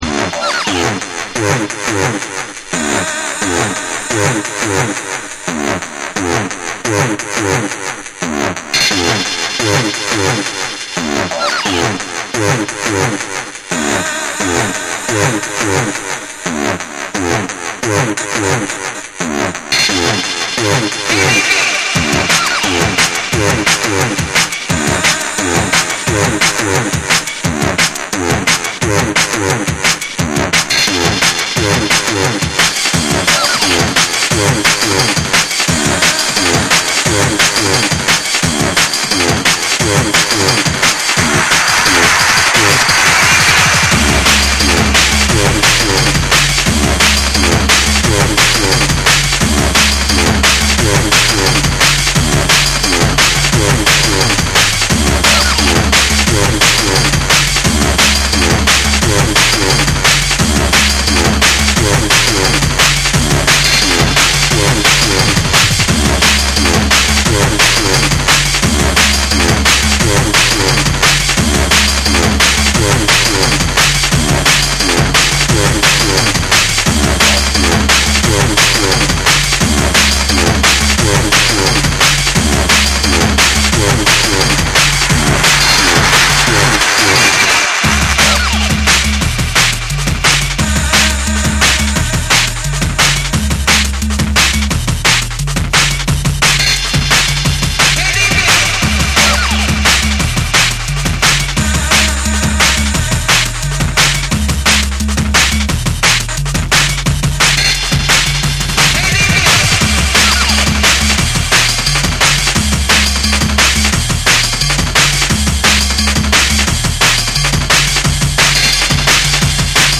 タイトに刻まれるドラムと低くうねるベースがフロアを引き締める
JUNGLE & DRUM'N BASS